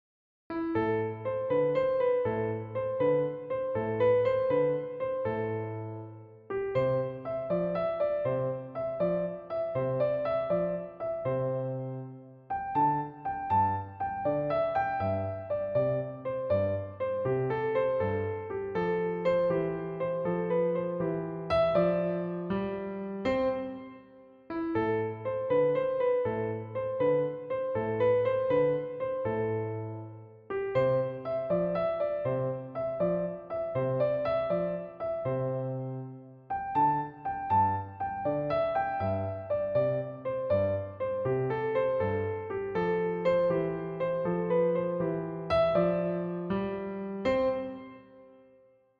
is a popular children's song.
instrumental versions